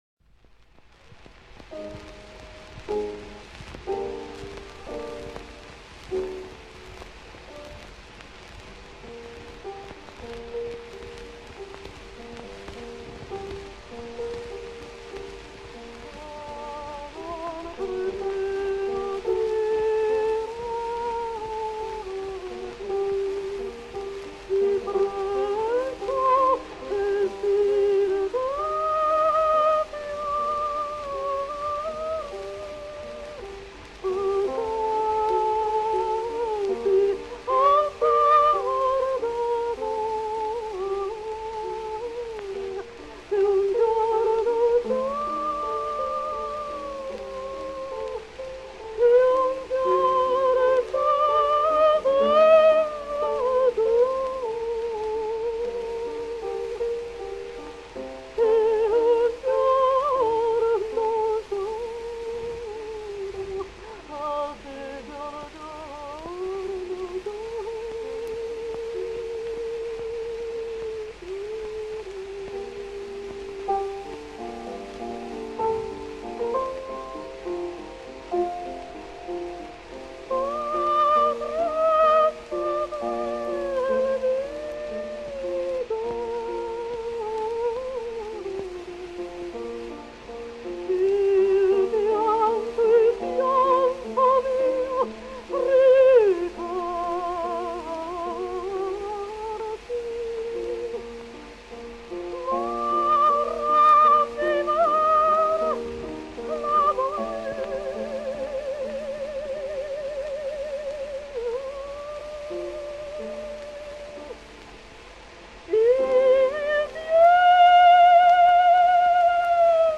1905년과 1906년, 파티는 그래머폰 & 타자 회사를 위해 웨일스 자택에서 30개 이상의 축음기 음반 (노래와 오페라 아리아, 일부 중복)을 녹음했으며, 육성 녹음(세 번째 남편에게 보낸 새해 인사, 기념품으로 간직)도 하나 남겼다.[9] 당시 그녀는 60대로, 1859년부터 시작된 바쁜 오페라 경력 이후 목소리는 전성기를 훨씬 지난 상태였다.
빈첸초 벨리니 작곡, 펠리체 로마니 대본, 아델리나 파티 노래 (1906년 녹음)
그럼에도 불구, 그녀의 음색은 맑고 깨끗했으며, 레가토 주법은 부드러워 인상적이었다. 이는 호흡 조절의 약화를 어느 정도 보완했다.[10] 음반에서는 생생한 가창력, 강한 흉성, 부드러운 음색도 확인할 수 있다. 그녀의 트릴은 유창하고 정확했으며, 발음도 훌륭했다.